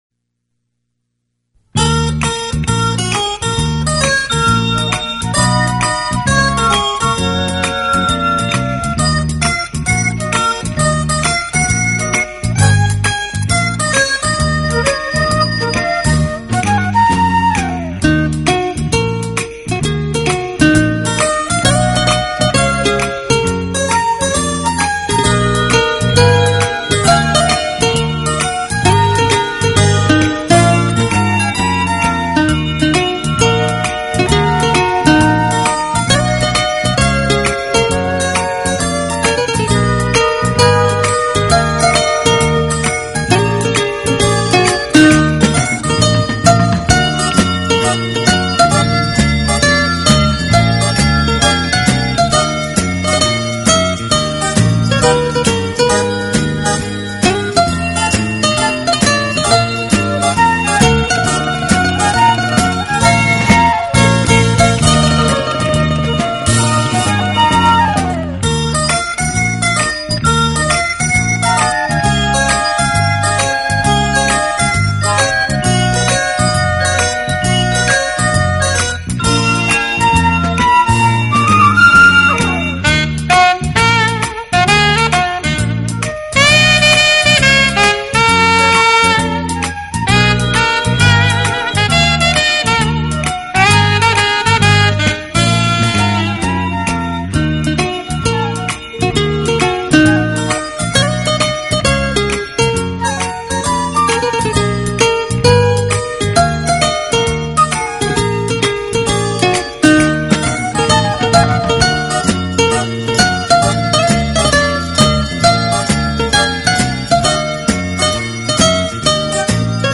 专辑音色清脆动人且温馨旖丽，不禁展示了精彩绝伦的空间感，而且带出吉他音箱共鸣声